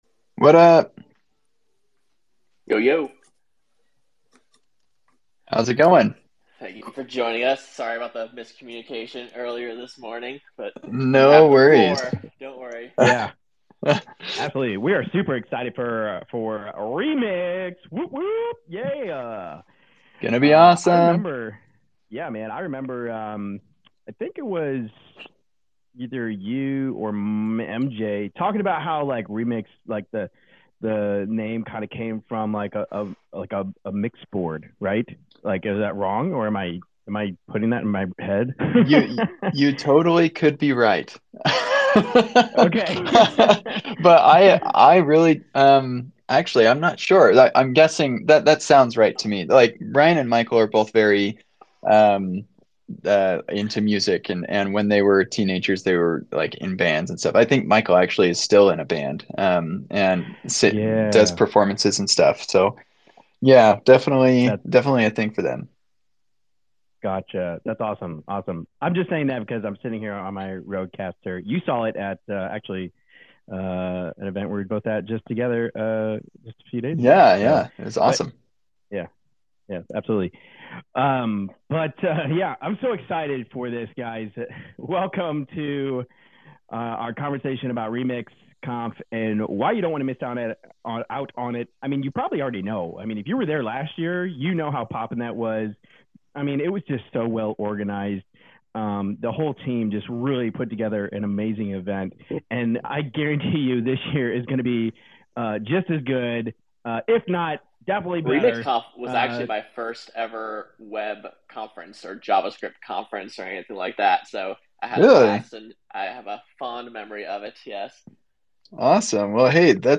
A conversation on why Remix Conf 2023 is unmissable with insights on the conference’s organization, the Remix community, and broader web topics